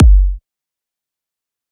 EDM Kick 11.wav